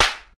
slap4.ogg